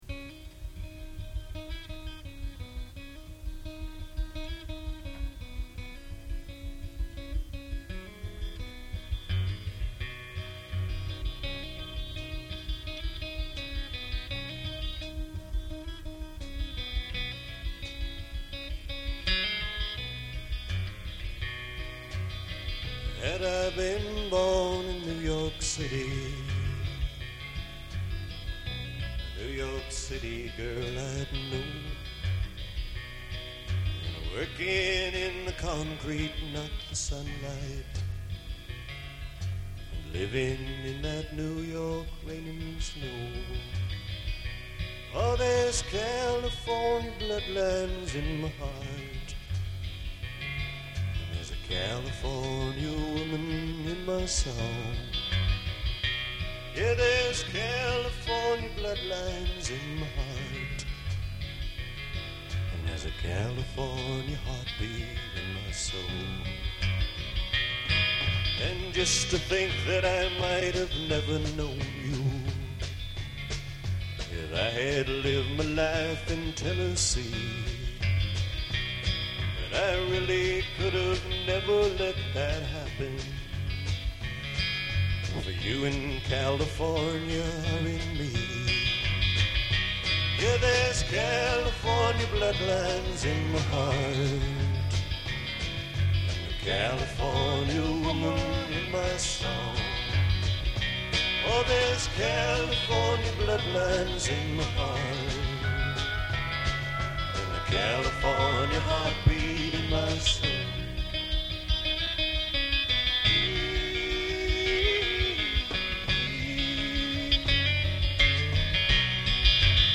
Selected Gig